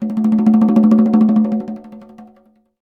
Index of /phonetones/unzipped/Samsung/Galaxy-Folder-2/notifications
Conga.ogg